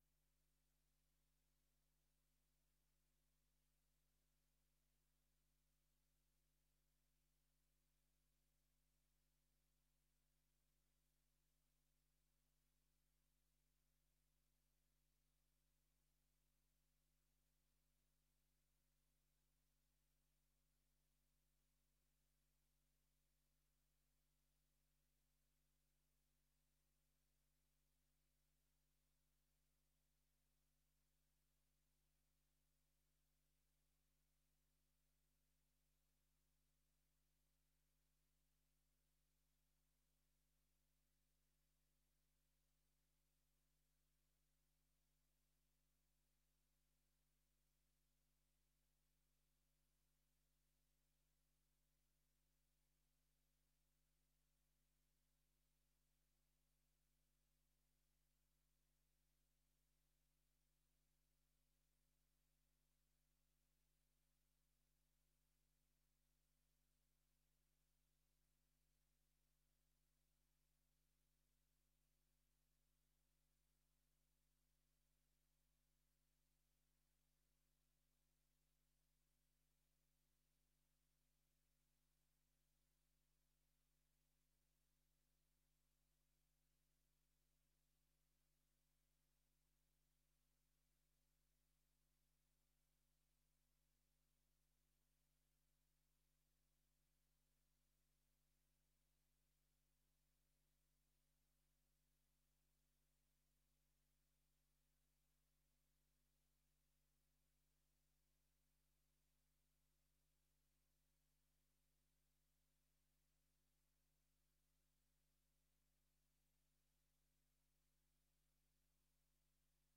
Extra raadsvergadering 10 december 2024 18:00:00, Gemeente Goirle
Locatie: Raadzaal